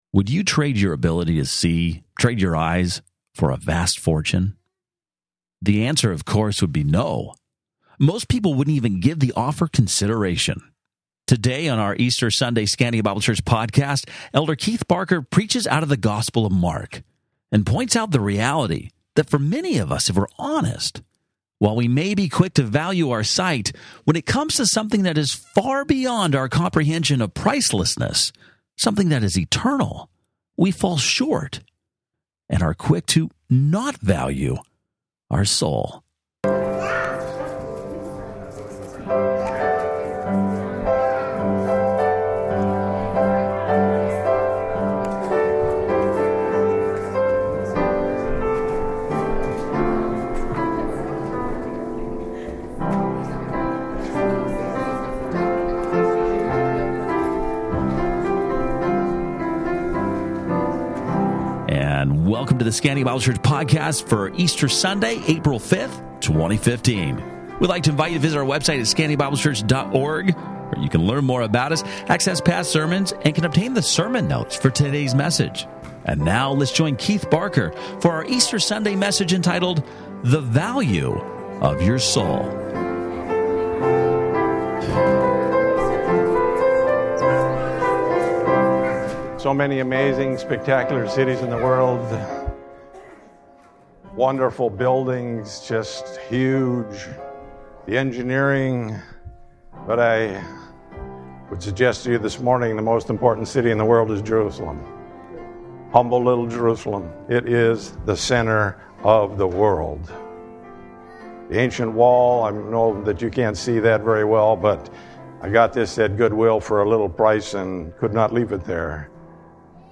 Sermon Notes Date